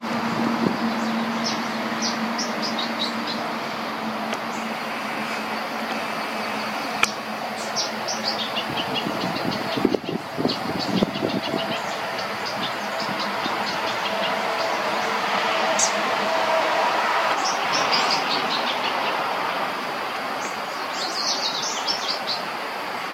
Southern Yellowthroat (Geothlypis velata)
Life Stage: Adult
Detailed location: Cercanías de la laguna Quetré Huitrú
Condition: Wild
Certainty: Photographed, Recorded vocal
Aranero-cara-negra.mp3